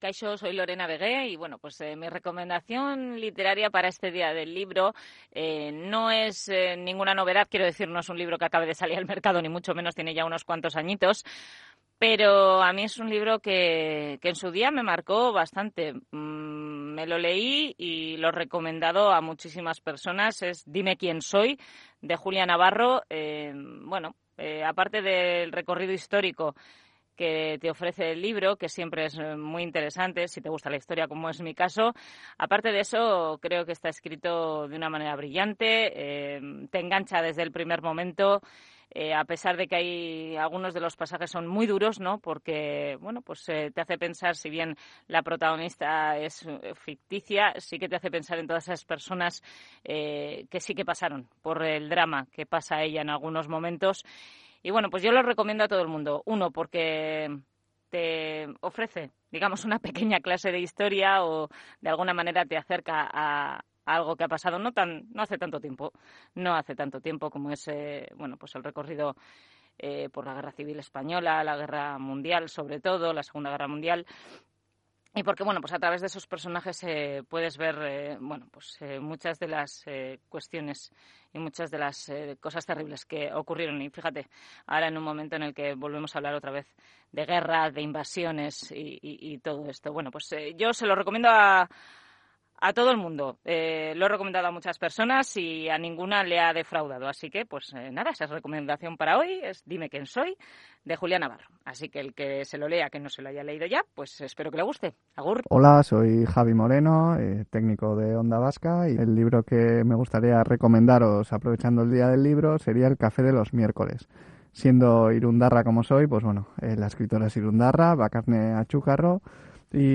Las recomendaciones de los locutores de Onda Vasca en el Día del Libro